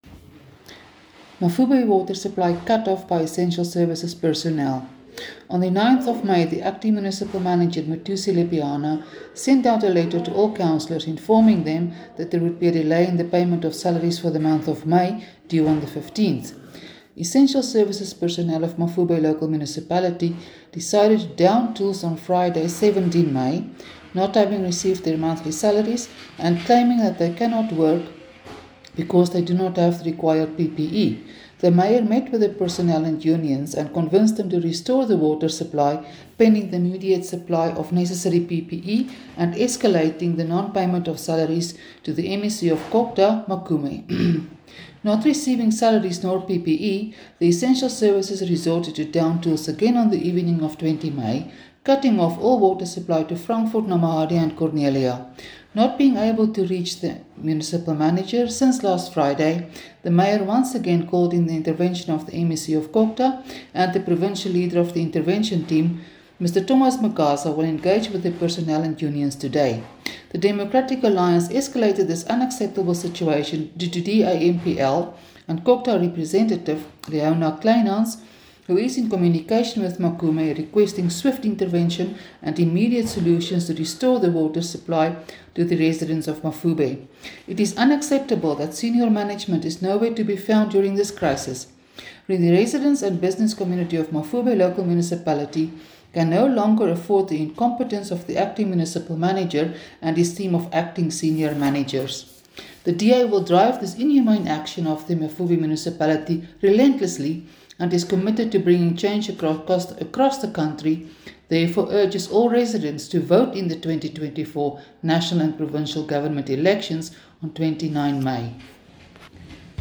English and Afrikaans soundbites by Cllr Suzette Steyn and